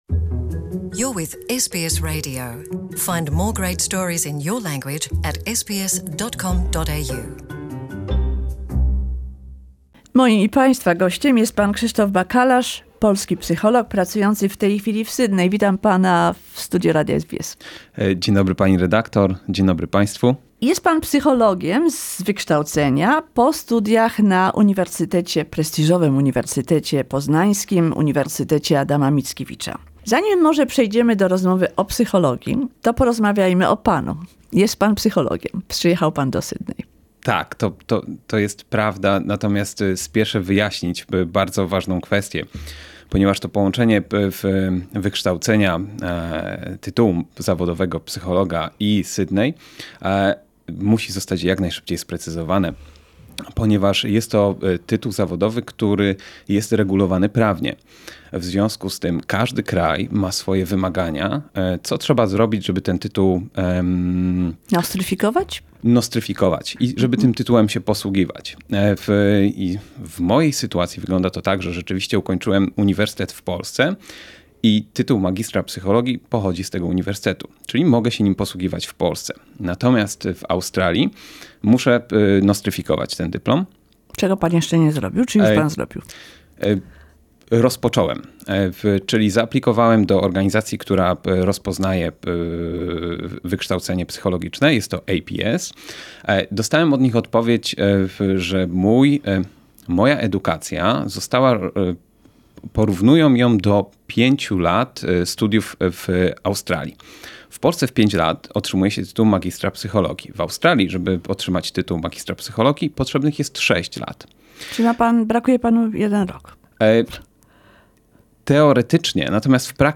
To pierwsza rozmowa z nowej serii.…